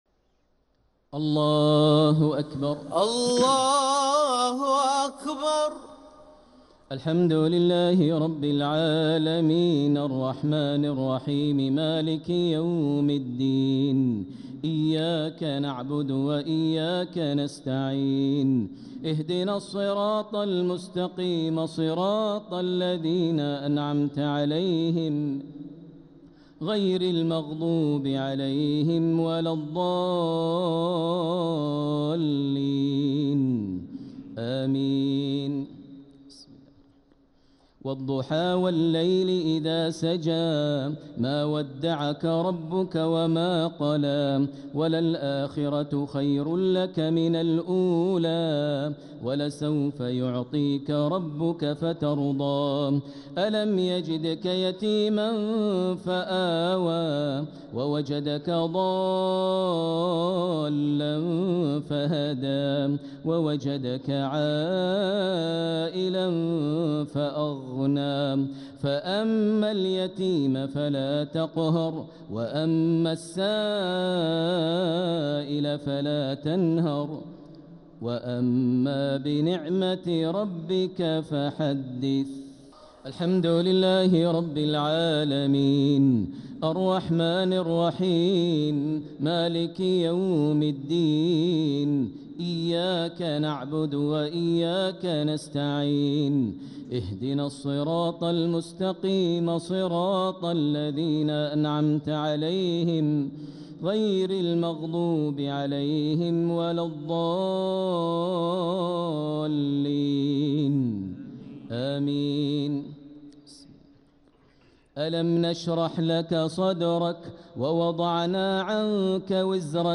صلاة الشفع و الوتر ليلة 28 رمضان 1446هـ > تراويح 1446 هـ > التراويح - تلاوات ماهر المعيقلي